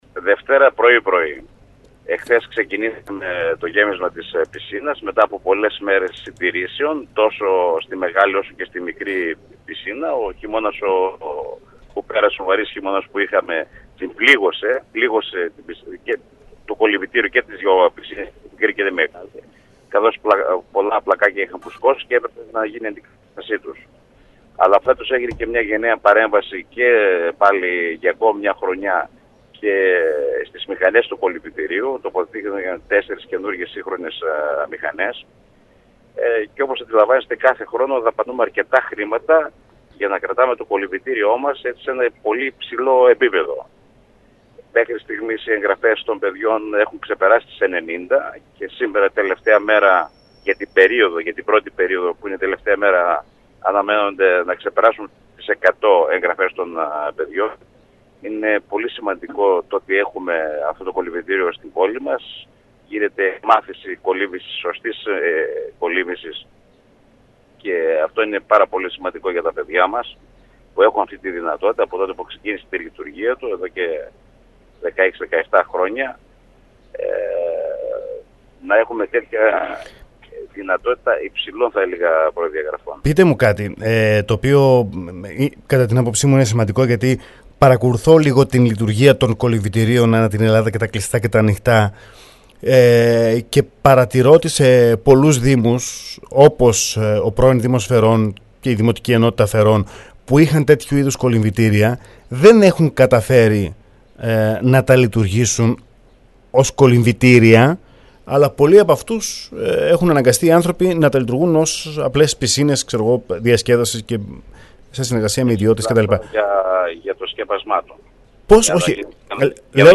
Ο Νίκος Γκότσης αντιδήμαρχος Φερών μίλησε